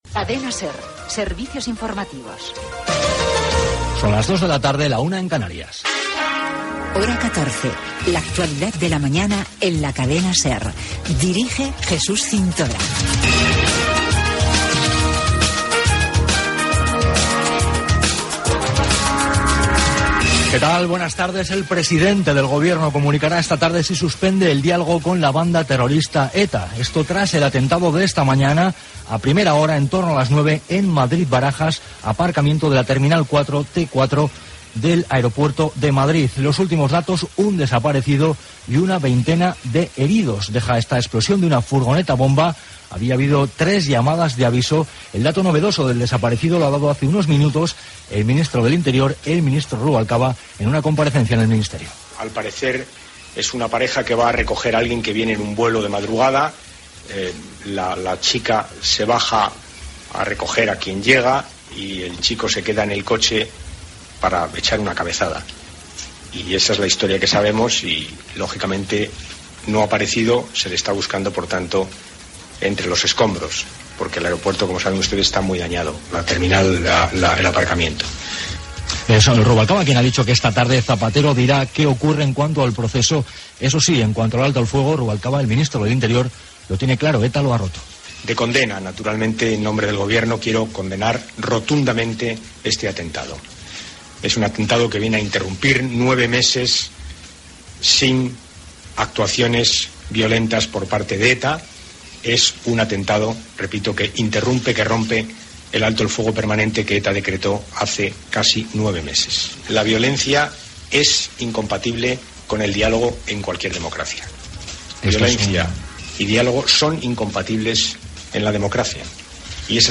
Careta d'entrada
Informatiu